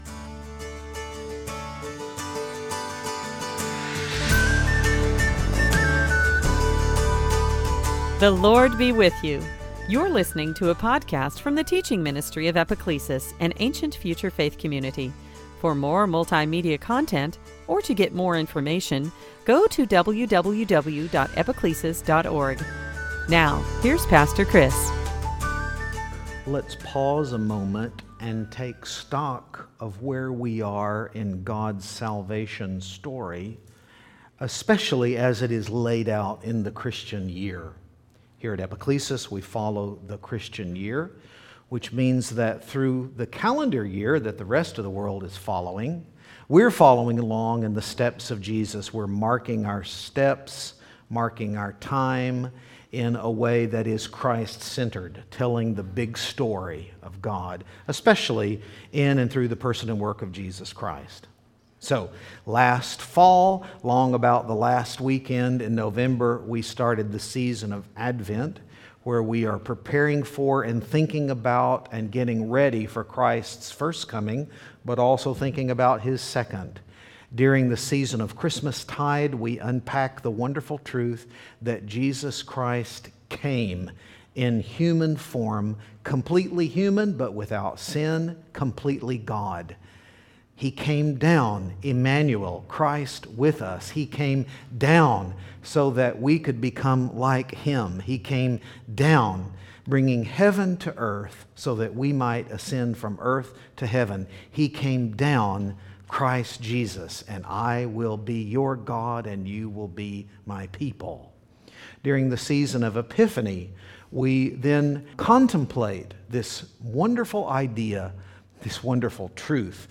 Series: Sunday Teaching Pentecost matters.